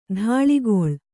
♪ dhāḷigoḷ